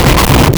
Player_Glitch [16].wav